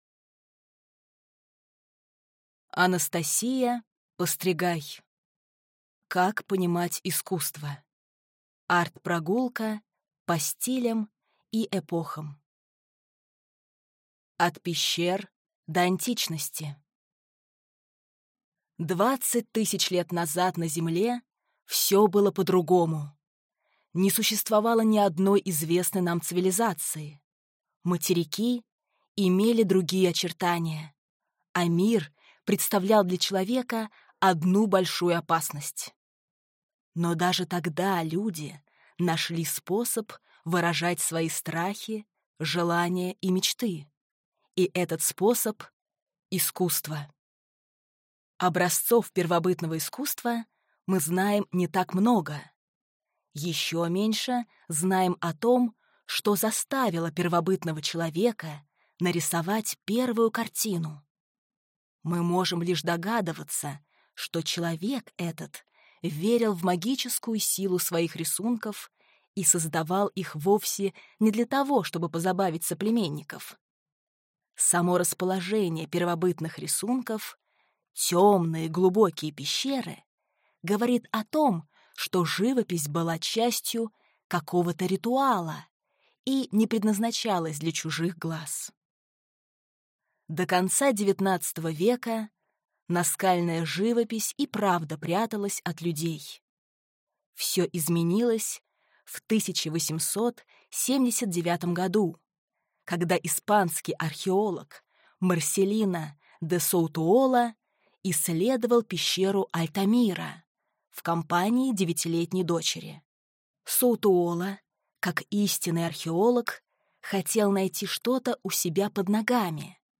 Аудиокнига Как понимать искусство: арт-прогулка по стилям и эпохам | Библиотека аудиокниг